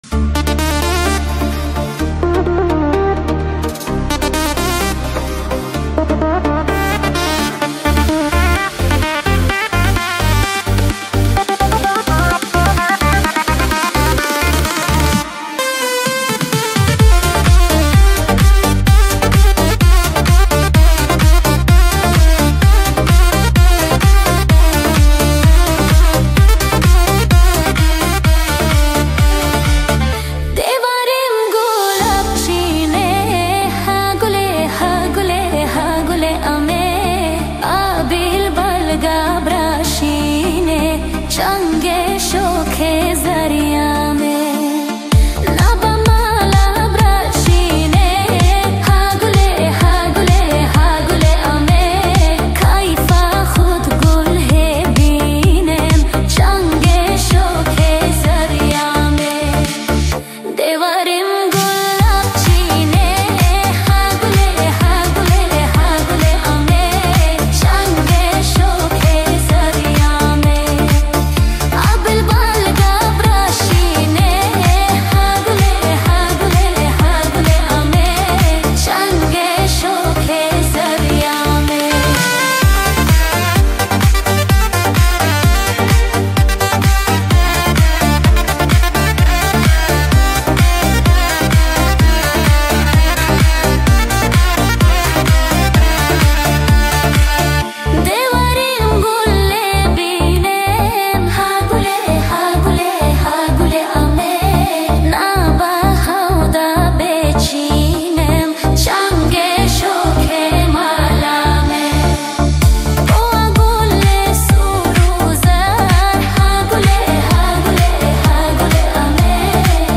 Kurdish Cover Remix